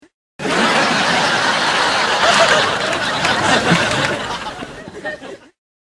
Laughs 2